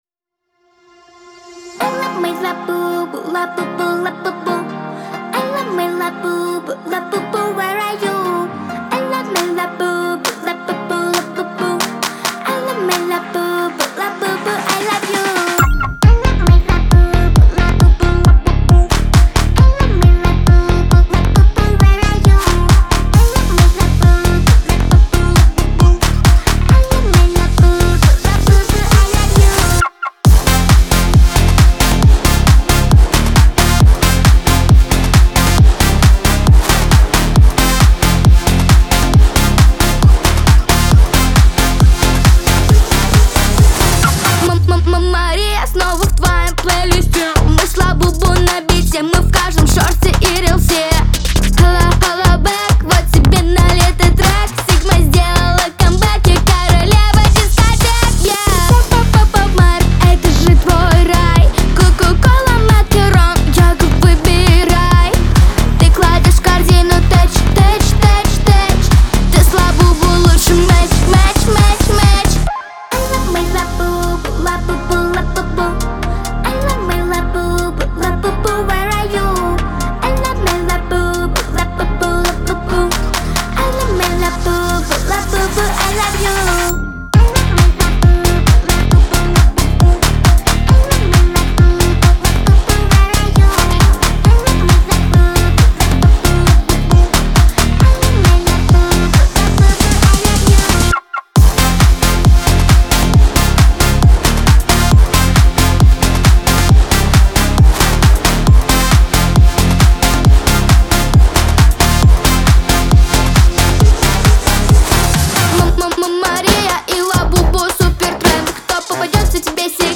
Веселая музыка
диско